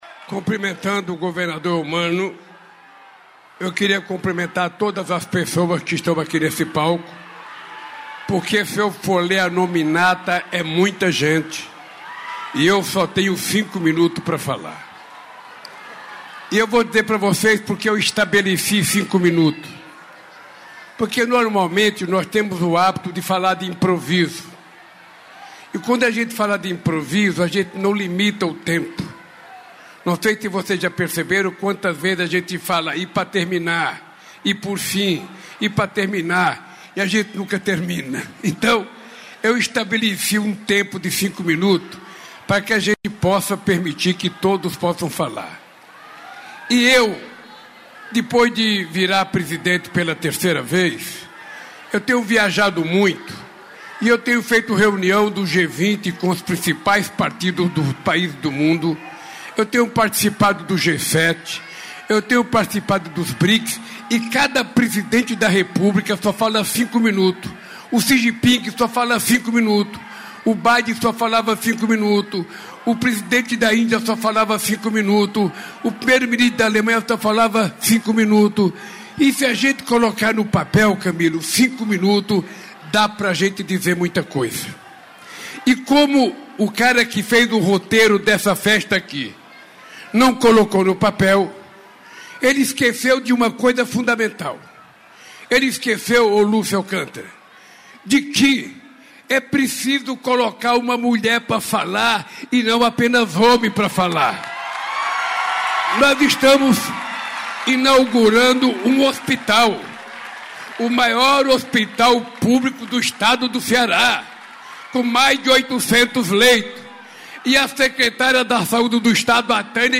Íntegra das palavras do presidente da República Luiz Inácio Lula da Silva na cerimônia de inauguração do Hospital Universitário do Ceará (HUC), em Fortaleza, nesta quarta-feira (19). Unidade prestará atendimento a casos de alta complexidade, além de dar suporte a outros hospitais públicos da Região.